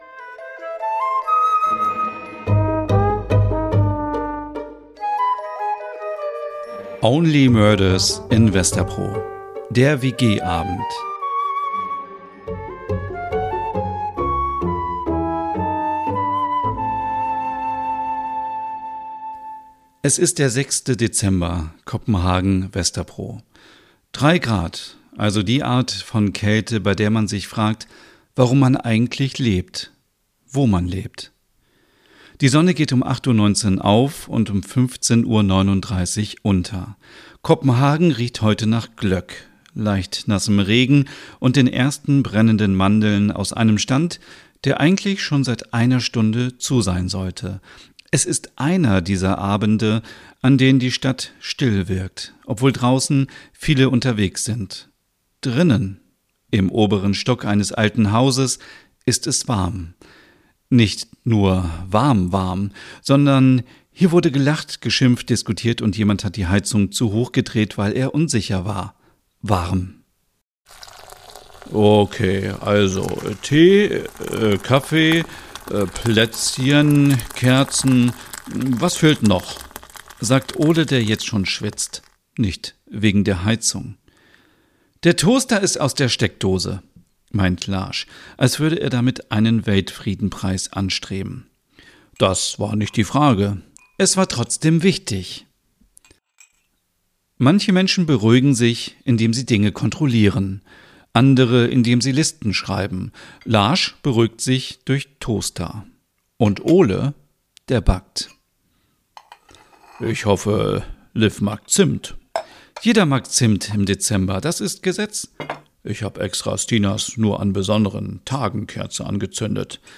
Dann entsteht Only Murders in Vesterbro: ein weihnachtliches Crime-Hörspiel voller nordischer Atmosphäre, schräger Charaktere und warmem Erzähler-Ton.